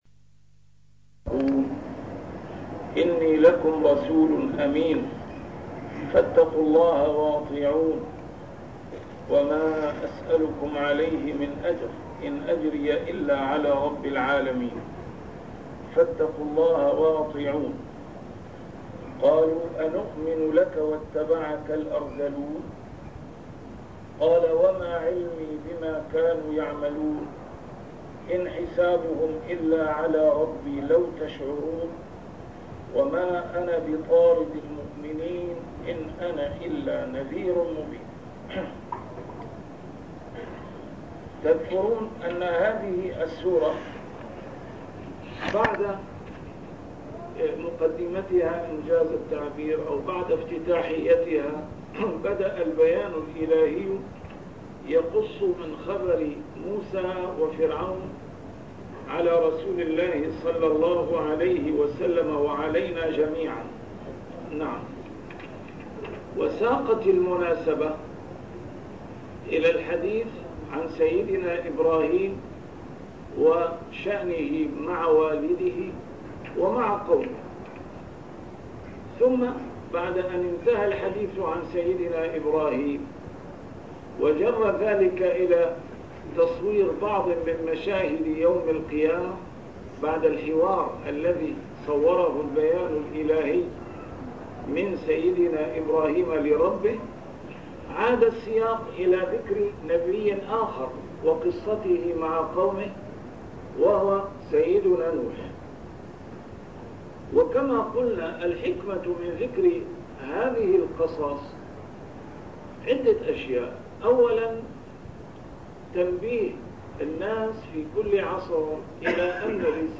A MARTYR SCHOLAR: IMAM MUHAMMAD SAEED RAMADAN AL-BOUTI - الدروس العلمية - تفسير القرآن الكريم - تسجيل قديم - الدرس 230: الشعراء 090-115